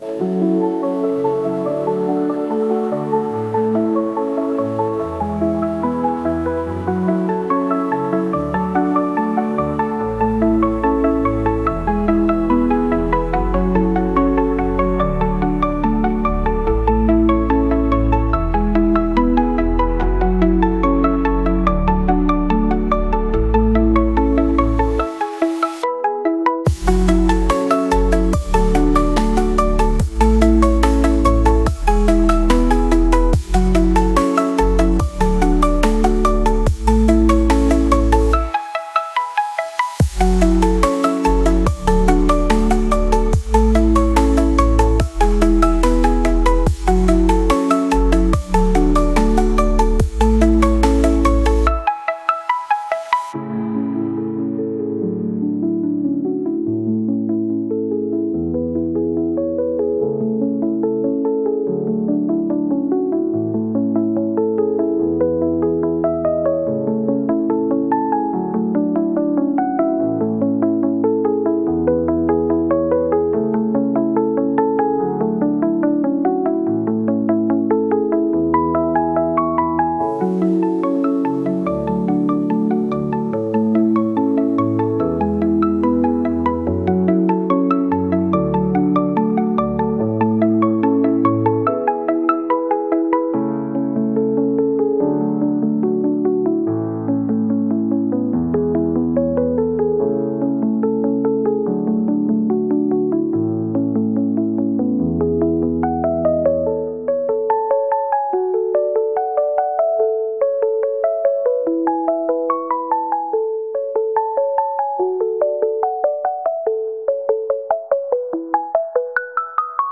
幻想的